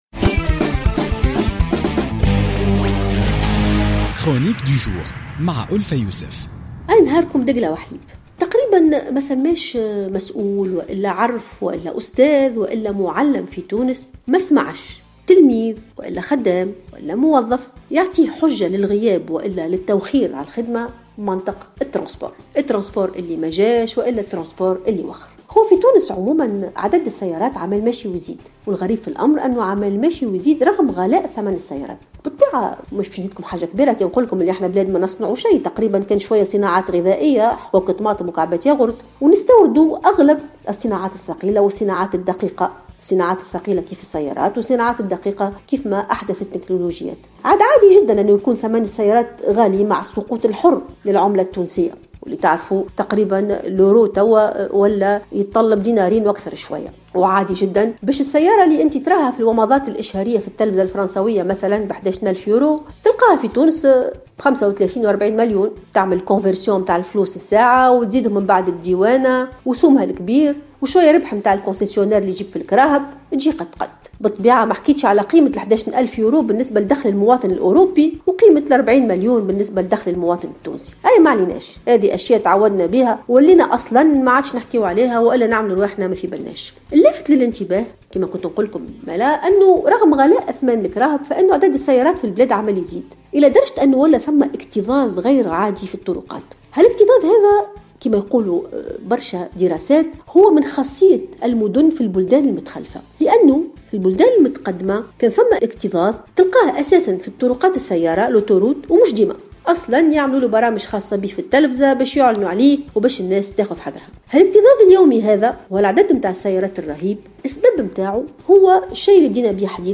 تطرقت الأستاذة الجامعية ألفة يوسف في افتتاحية اليوم الثلاثاء 16 فيفري 2016 لمشكل التنقل في تونس والإكتظاظ اليومي وما ينجر عن ذلك من غيابات الموظفين وتأخرهم عن عملهم.